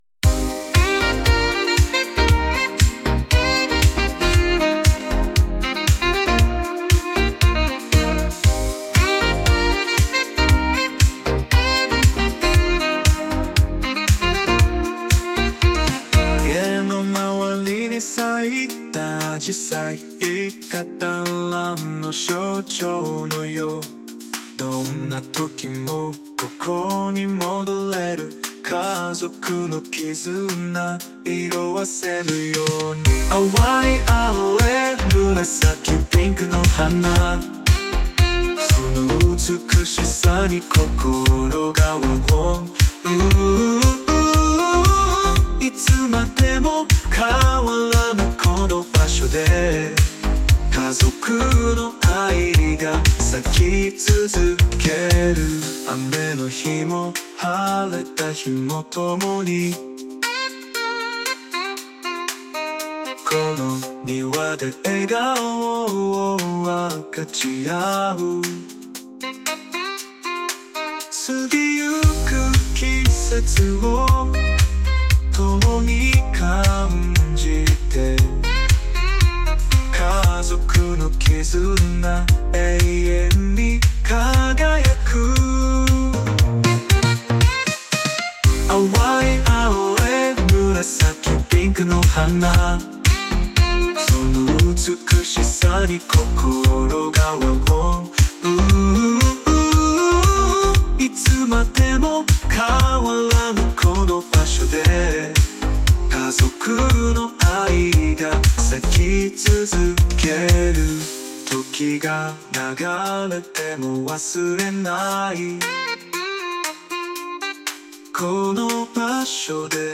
曲全般：SunoAI